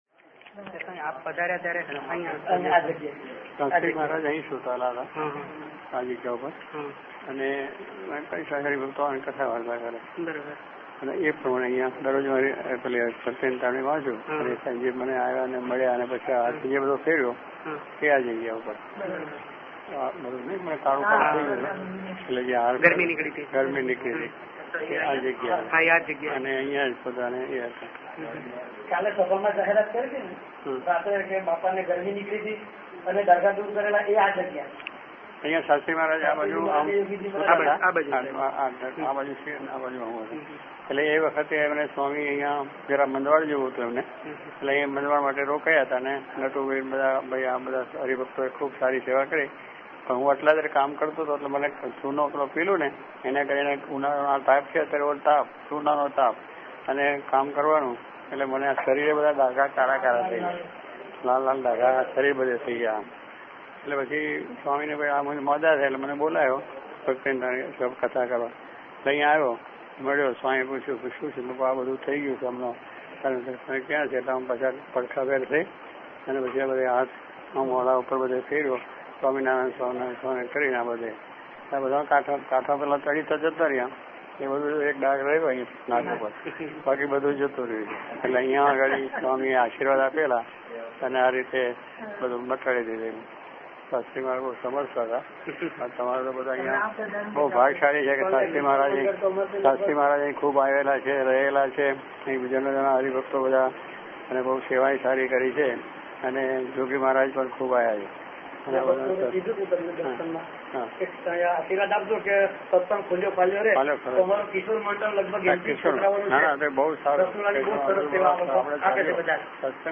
Swamishri performs arti at Hari mandir in Jadeshwar   Swamishri narrates the incident on how the red spots that had developed by pounding limestone in Atladra had disappeared due to the blessings of Shastriji Maharaj Audio